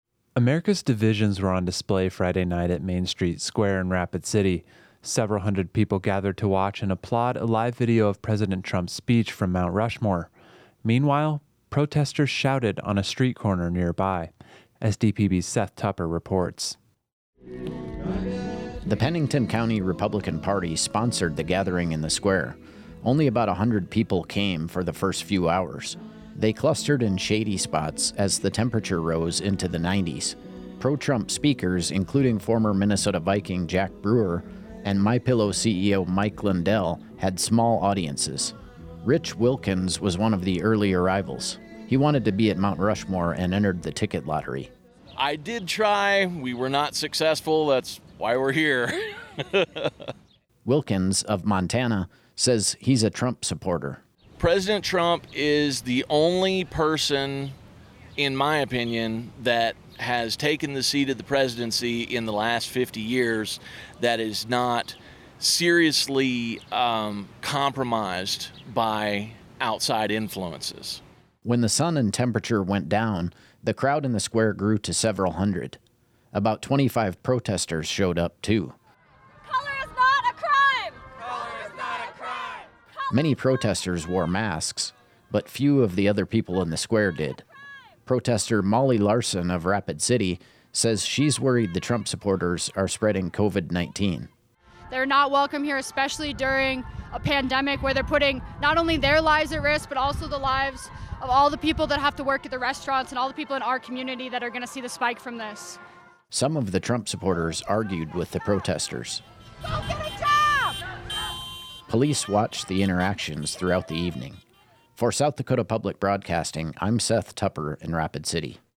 A Trump supporter and protesters shout at each other.